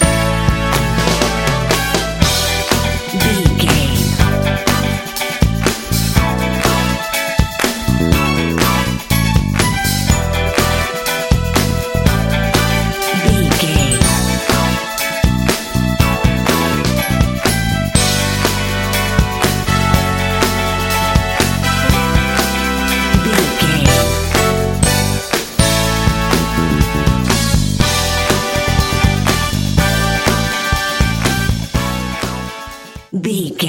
Aeolian/Minor
funky
groovy
driving
energetic
lively
strings
bass guitar
electric guitar
drums
brass